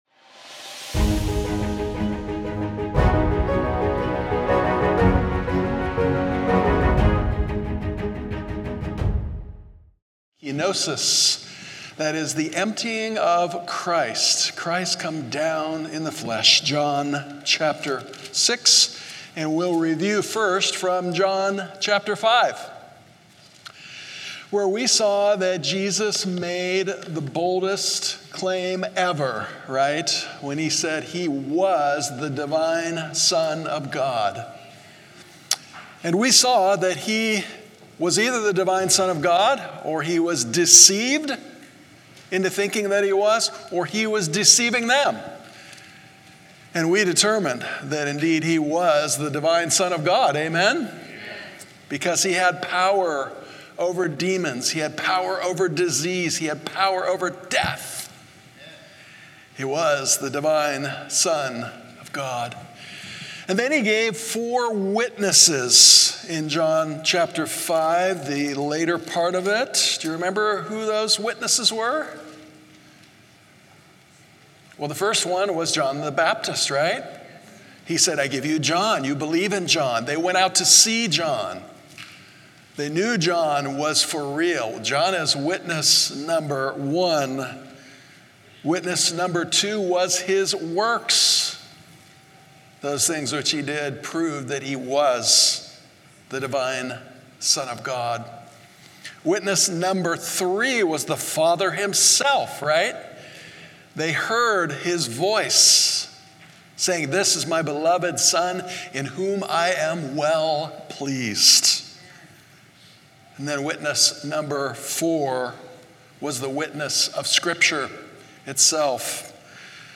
A message from the series "Central Sermons."
From Series: "Central Sermons"